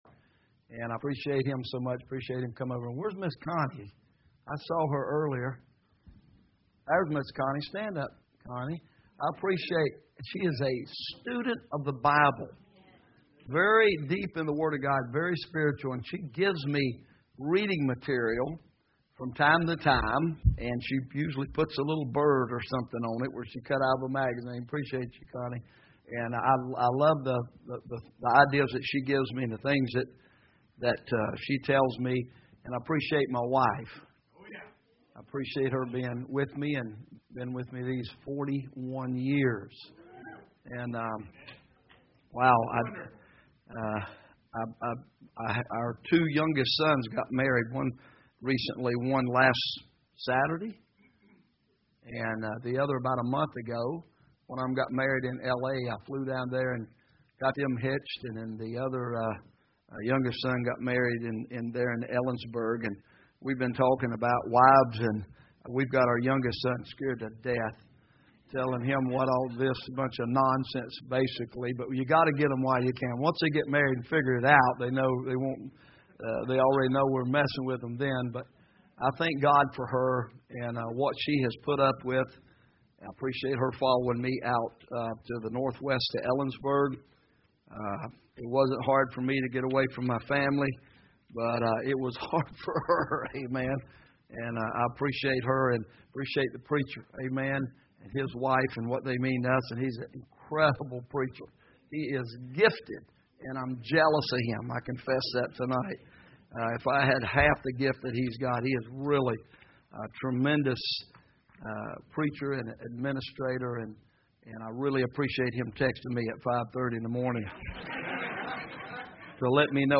“Why Not Halleujah?” from Jubilee 2013